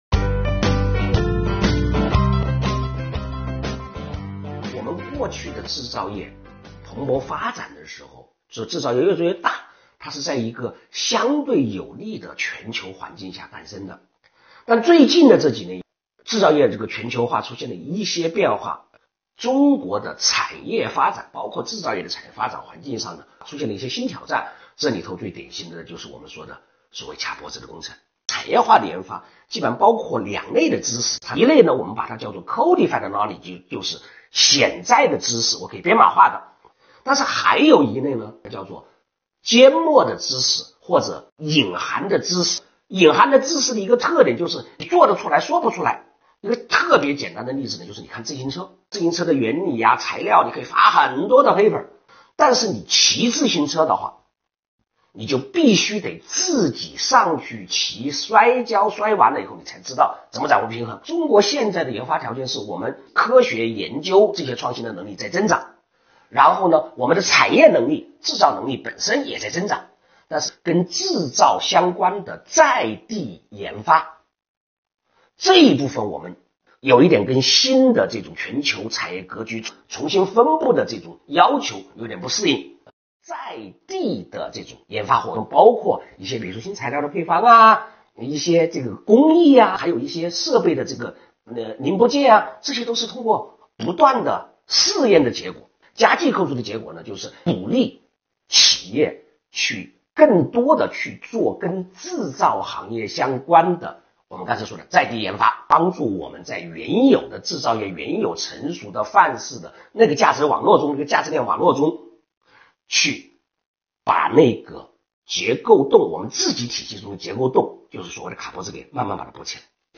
在视频访谈中